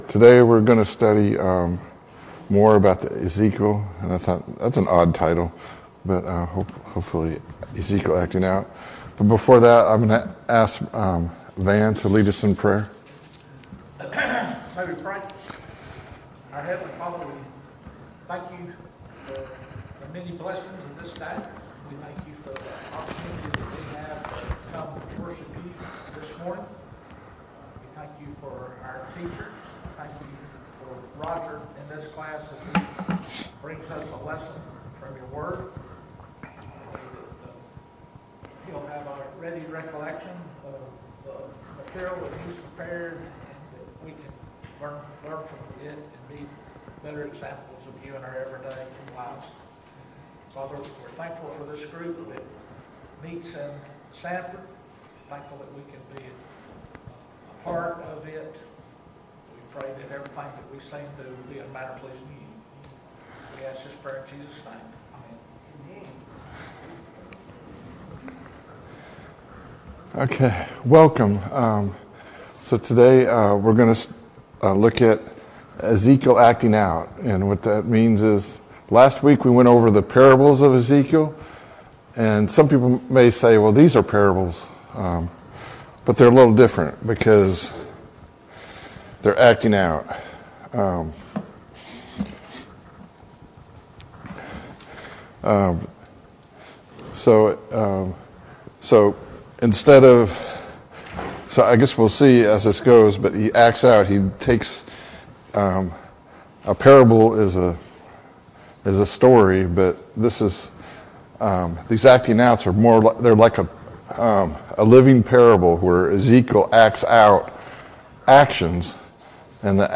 Study of Ezekiel Service Type: Sunday Morning Bible Class « Study of Paul’s Minor Epistles